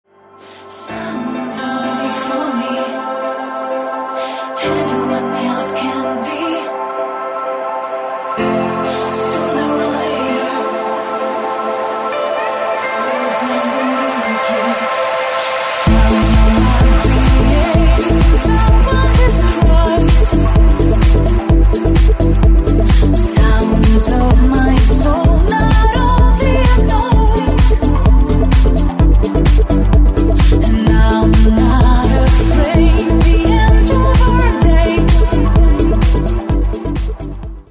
красивые
женский вокал
dance
Electronic
progressive house
vocal trance